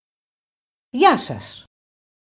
Just click on the languages below to hear how to pronounce “Hello”.